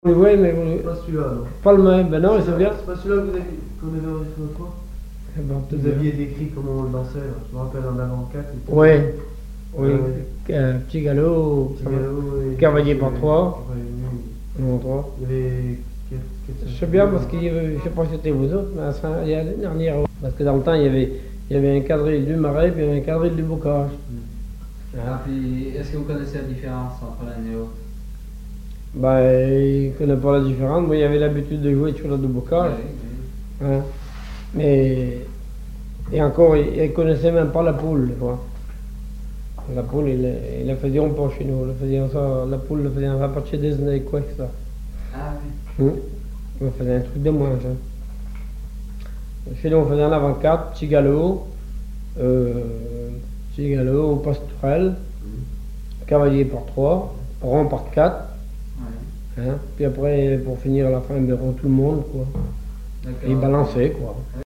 Répertoire sur accordéon diatonique